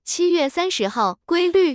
tts_result_14.wav